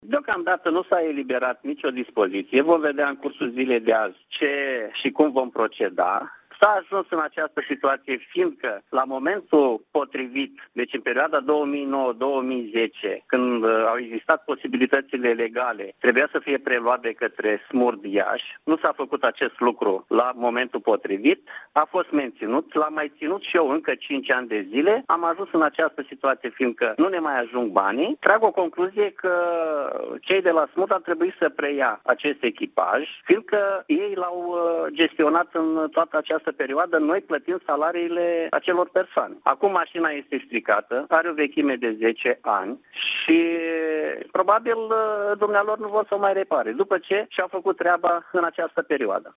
Primarul Constantin Istrate a spus că o altă problemă cu care se confruntă cei de la substaţia SMURD din Podu Iloaiei este faptul că la sfârşitul săptămânii trecute autospeciala s-a defectat.